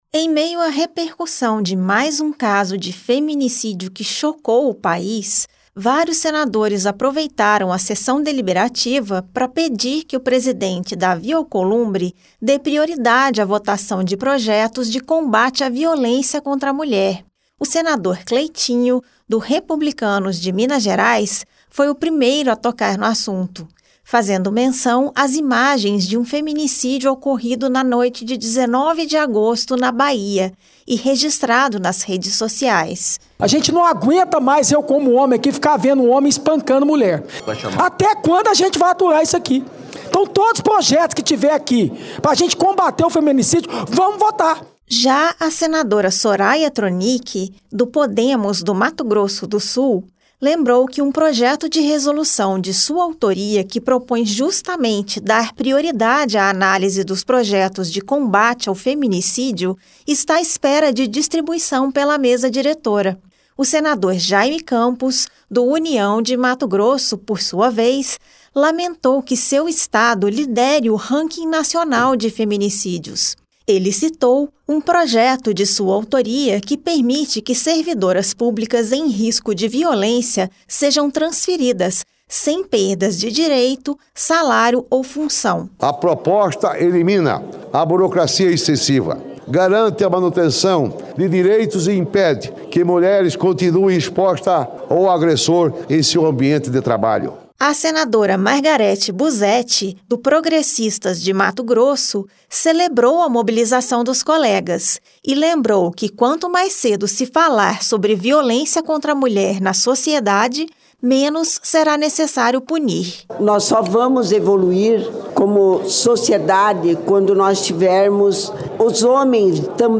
Os senadores Cleitinho (Republicanos-MG), Soraya Thronicke (Podemos-MS), Jayme Campos (União-MT) e Margareth Buzetti (sem partido-MT) pediram na sessão deliberativa desta quarta-feira (20) que o presidente Davi Alcolumbre priorize na pauta os projetos sobre combate à violência contra a mulher. O tema foi levado ao Plenário pelo senador Cleitinho, diante da repercussão das imagens de um feminício ocorrido na Bahia na noite de terça-feira (19).